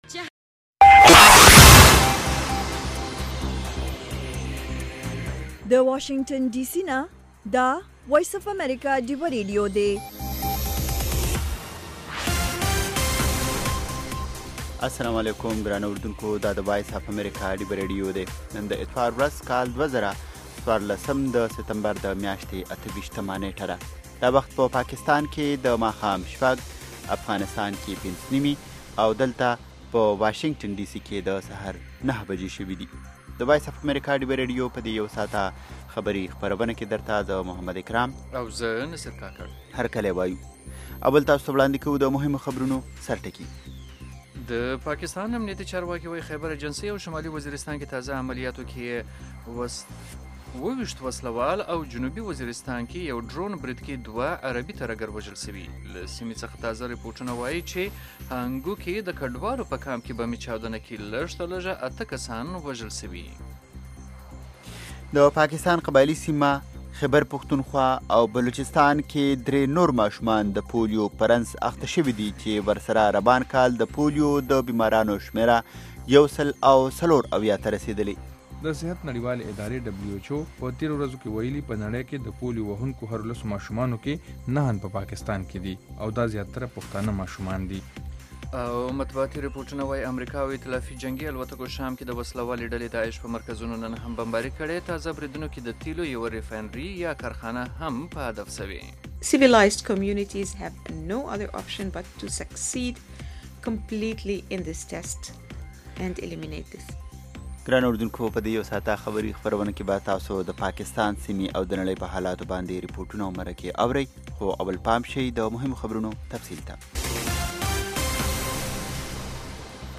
خبرونه - 1300